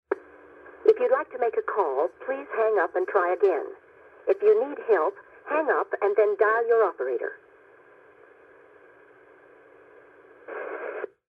Operator in English says the subscriber is unavailable